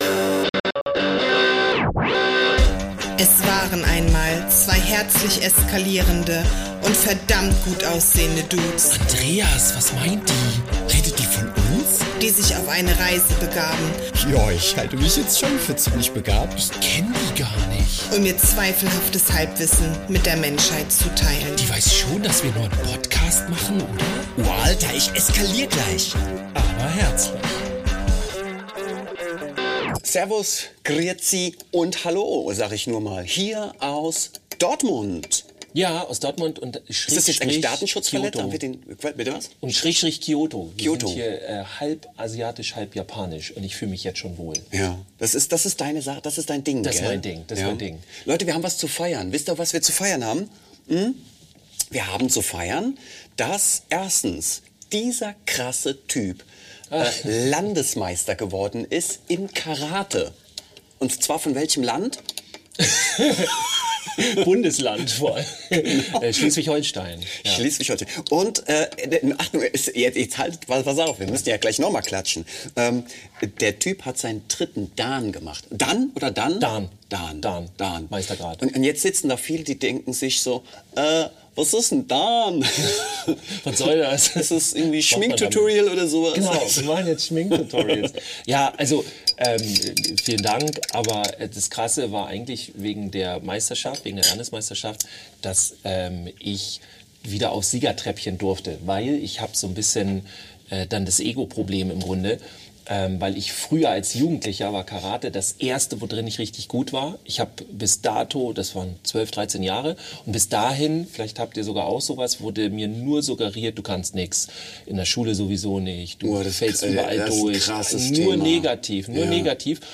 Beschreibung vor 2 Jahren In der achten Episode senden wir aus Dortmund und Kyoto gleichzeitig und zwar aus dem schönen Hotel Ambiente. Wir reden über lebende Bienen, über Kindheit, wie man diese aufarbeitet, über innere Trigger und über unsere Superkräfte. Wir sprechen über Gefühle, Emotionen, Glaubenssätze und was davon fremd und was unser Eigenes ist.